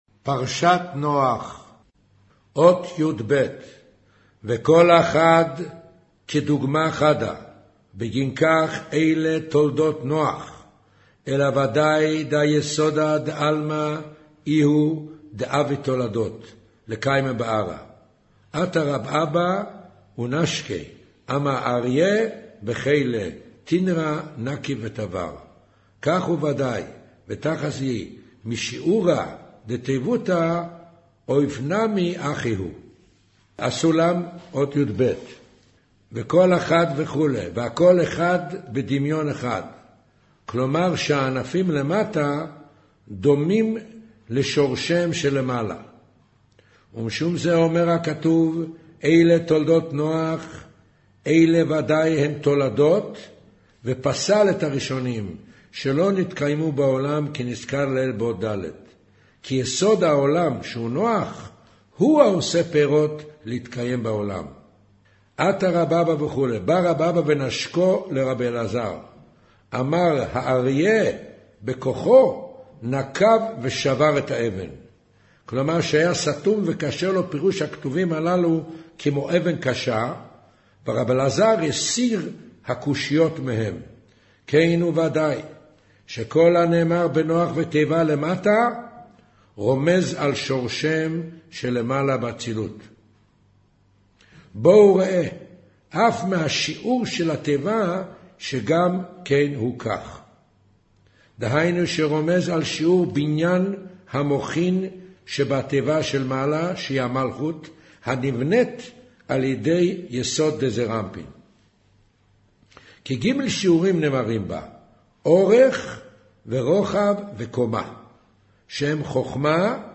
אודיו - קריינות זהר פרשת נח מאמר נח ותיבה אות יב'